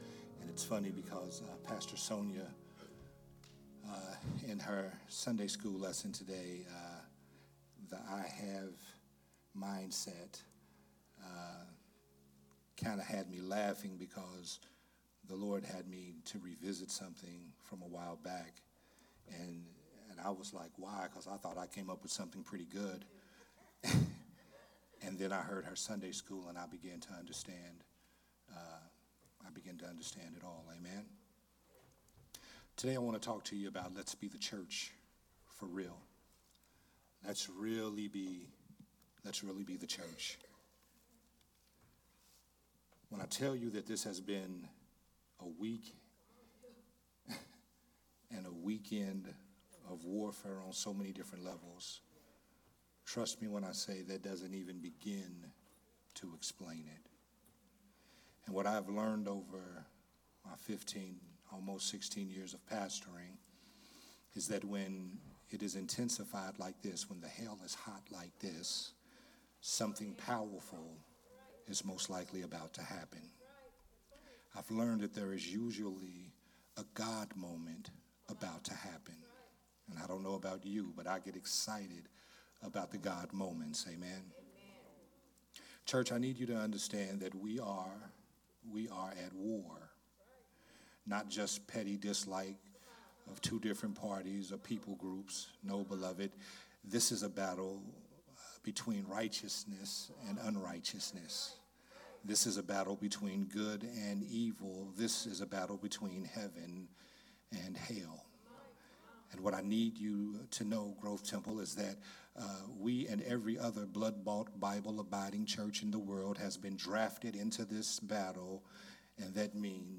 a Sunday Morning Worship Service message